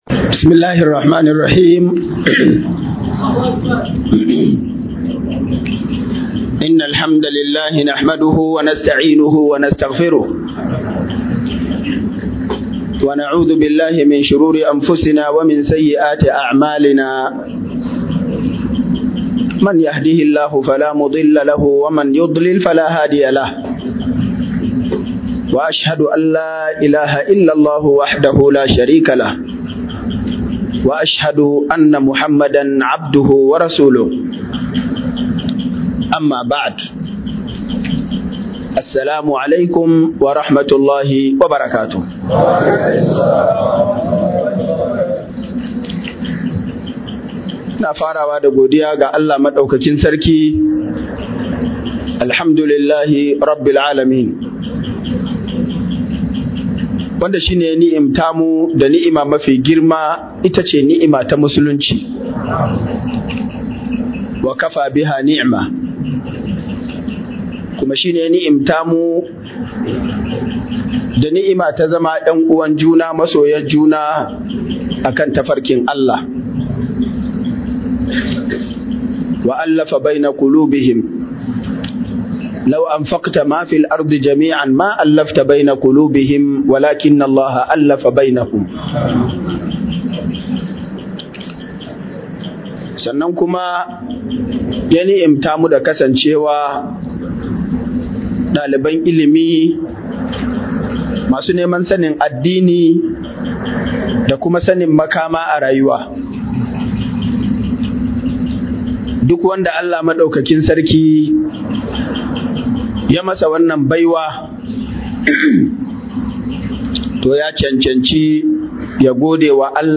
CAPACITY - MUHADARA